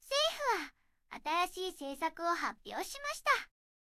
referenceの音声と書き起こしを入力して、Targetのテキストを生成してみました。結構いい感じですが、起伏がオーバーになることが多いのが少し気になりました。
色々パラメータ変えて出力を見てみました。全部同じじゃないですか！